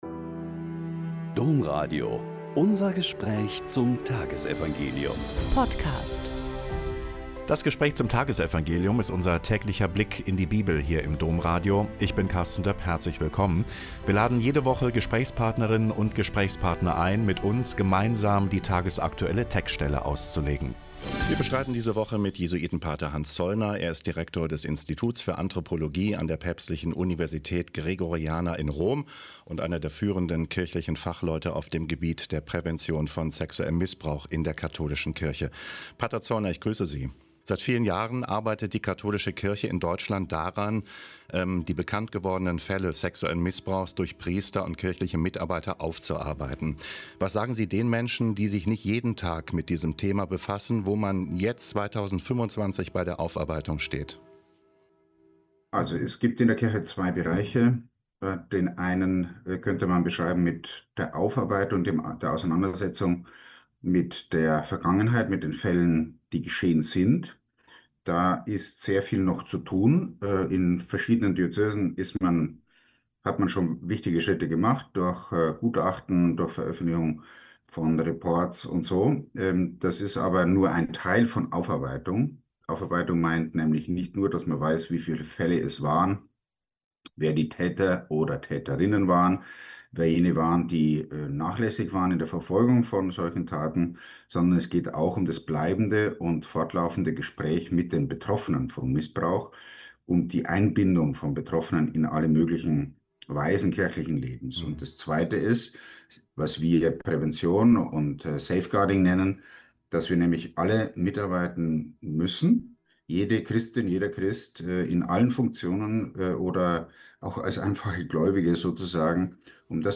Mt 5,20-26 - Gespräch